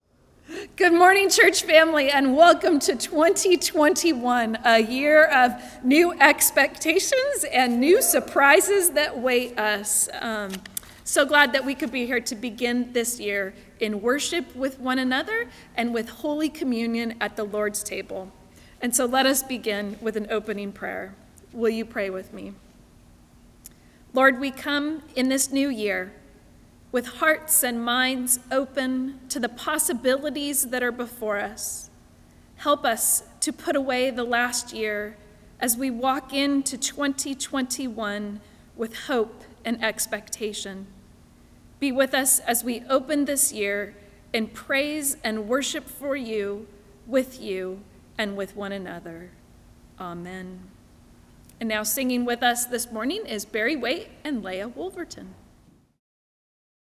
Service of Worship
Welcome and Opening Prayer
Opening Prayer
open_prayer.mp3